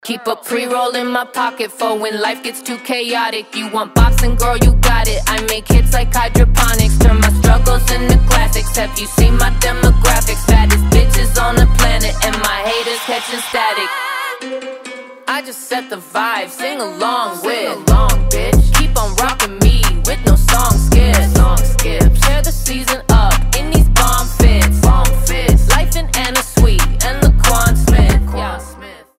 женский голос
качающие